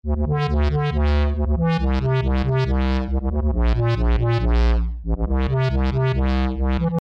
翘曲晃动
描述：Reason第4版的Thor合成器使用2个方形模拟振荡器。
Tag: 138 bpm Garage Loops Bass Loops 1.17 MB wav Key : Unknown